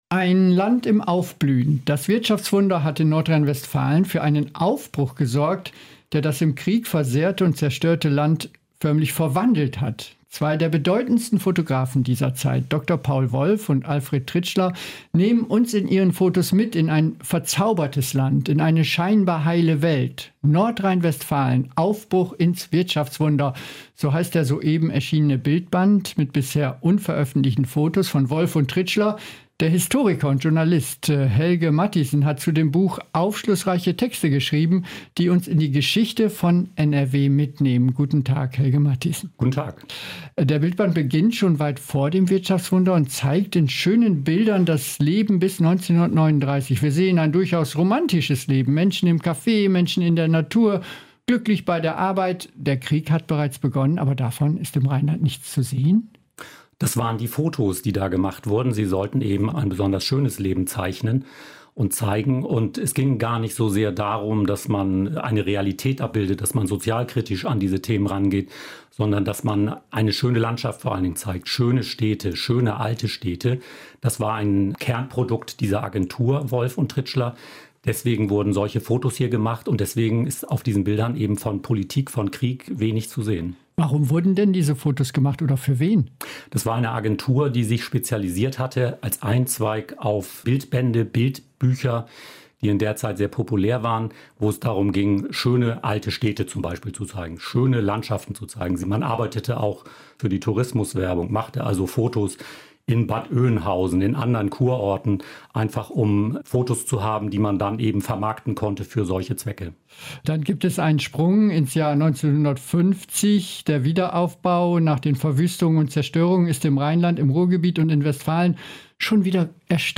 Interview. Besonders im Ruhrgebiet wurden so viele Kirchen gebaut wie nie zuvor.